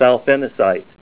Help on Name Pronunciation: Name Pronunciation: Thalfenisite + Pronunciation
Say THALFENISITE